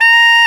SAX TENORF1Q.wav